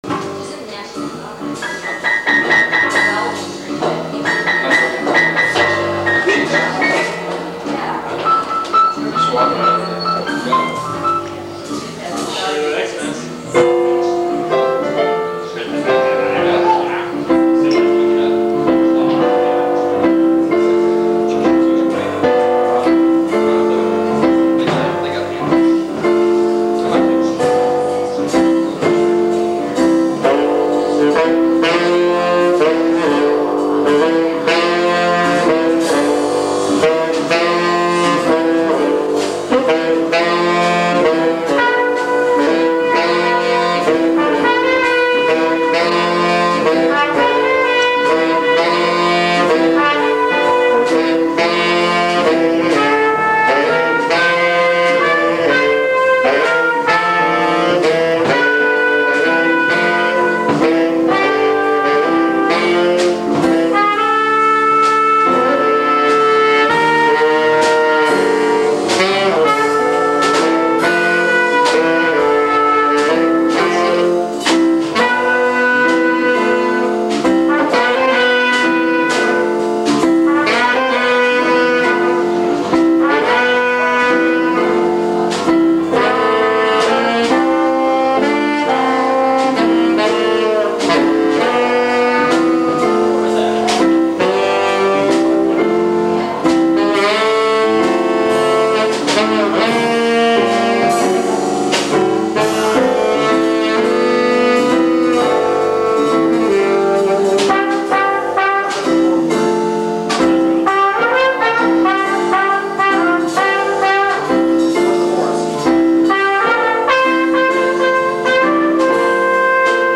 poetry, sax
trumpet
keyboards
bass
drums